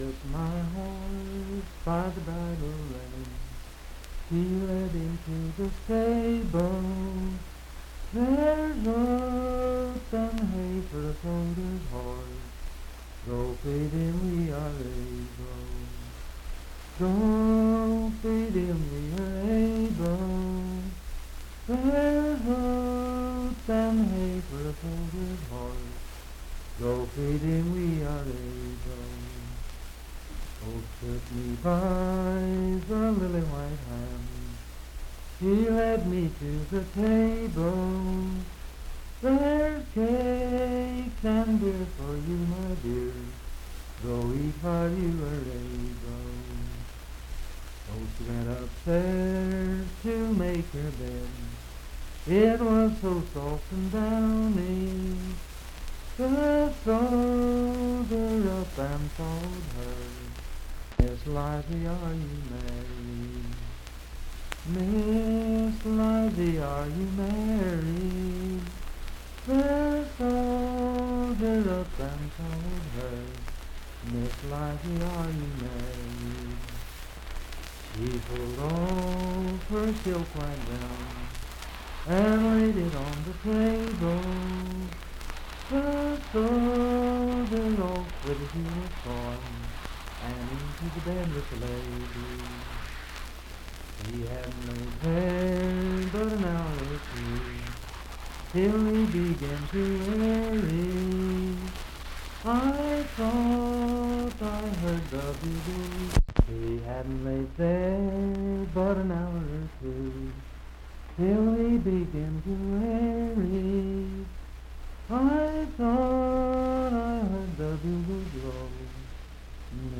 Unaccompanied vocal music
Verse-refrain 7(4).
Voice (sung)
Marlinton (W. Va.), Pocahontas County (W. Va.)